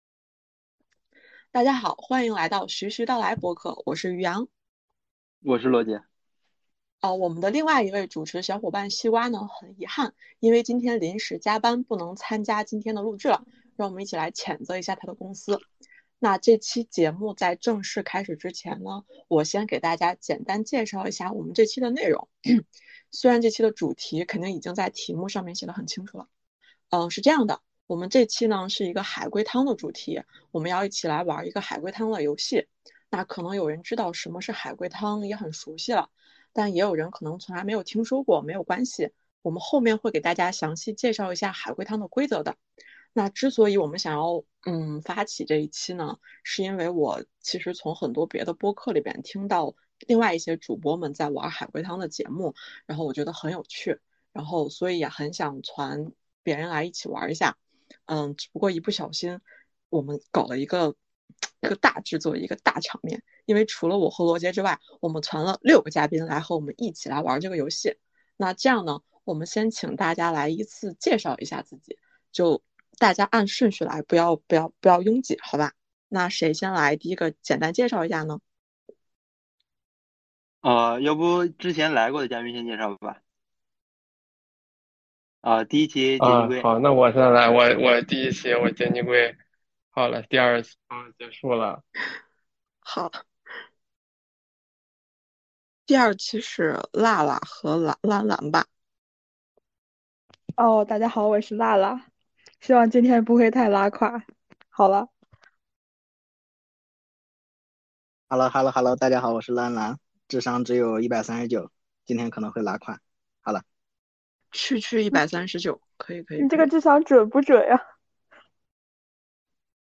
在本期中，我们会轮流出一些汤面，大家一起大开脑洞，还原这些故事。